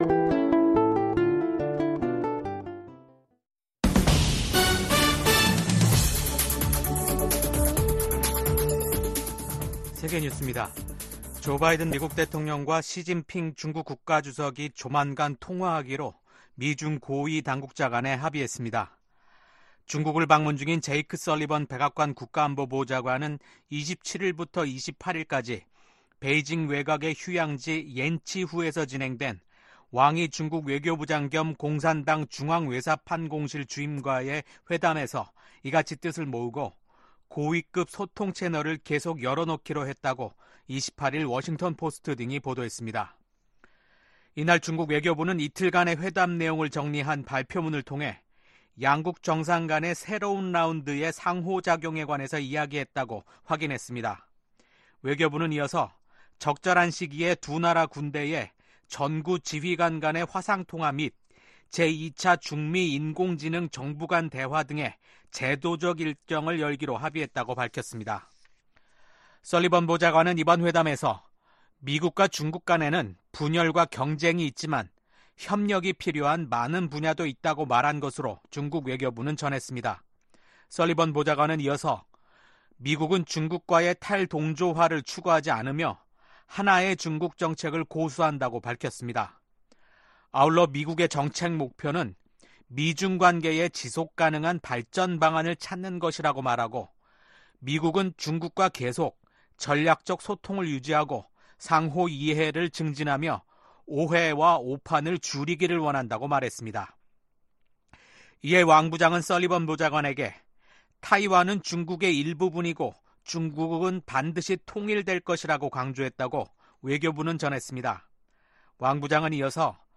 VOA 한국어 아침 뉴스 프로그램 '워싱턴 뉴스 광장' 2024년 8월 29일 방송입니다. 북한이 잠수함 10여 척을 국제해사기구(IMO)에 처음으로 등록했습니다. 북한은 김정은 국무위원장 참관 아래 ‘서울 불바다’ 위협 당시 거론됐던 240mm 방사포에 새롭게 유도 기능을 적용한 신형 방사포 성능을 과시했습니다. 미국 정부가 북한이 처음으로 공개한 자폭 드론 위협을 심각하게 여긴다며 계속 주시할 것이라고 밝혔습니다.